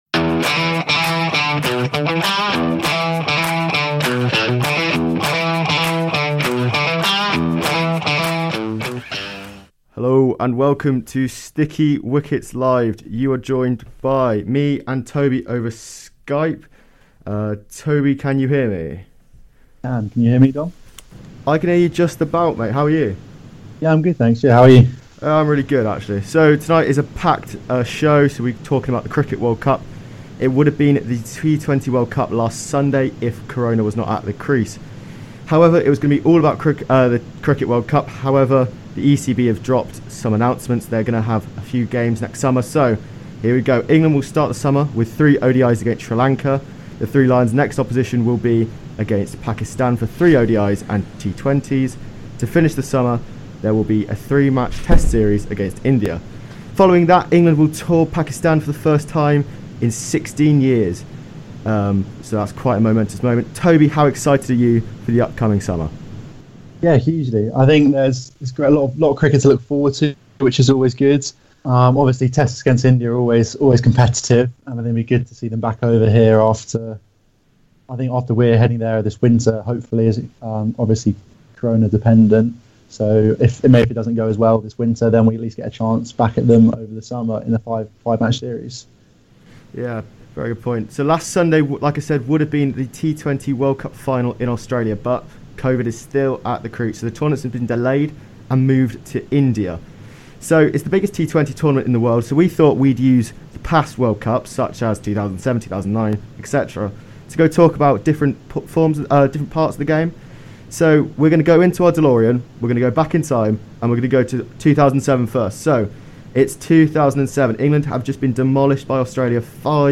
first live episode of URN's cricket podcast, Sticky Wicket.